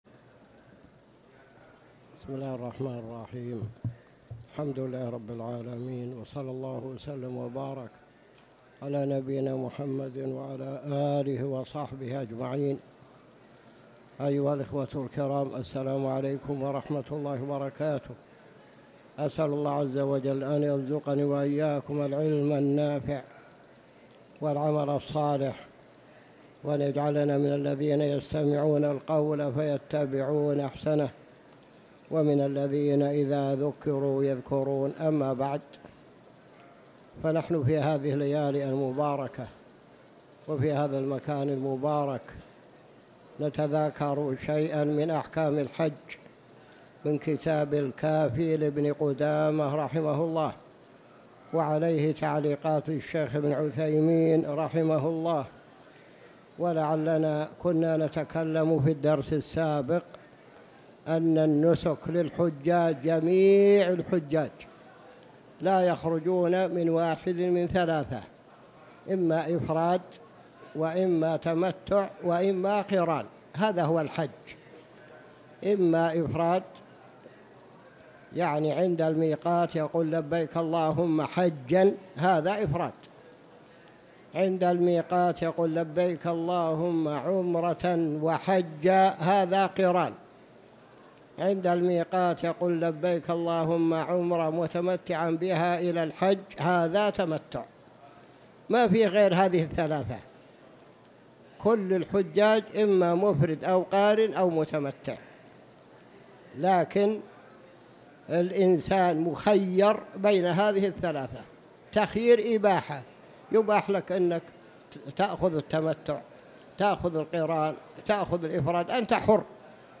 تاريخ النشر ٢٧ ذو القعدة ١٤٤٠ هـ المكان: المسجد الحرام الشيخ